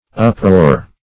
Uproar \Up"roar\ ([u^]p"r[=o]r`), n. [D. oproer; akin to G.
Note: [In verse, sometimes accented on the second syllable.]